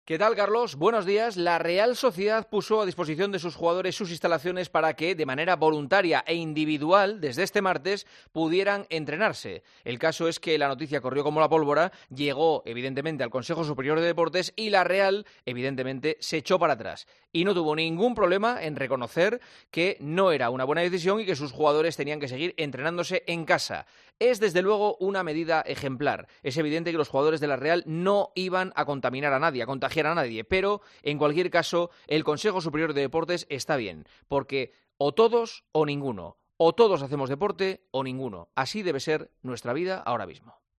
Así lo ha anunciado este lunes el presidente Emmanuel Macron en una declaración televisada para todo el país